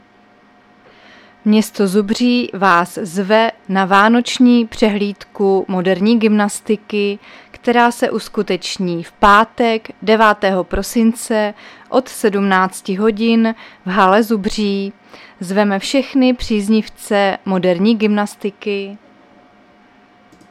Záznam hlášení místního rozhlasu 9.12.2022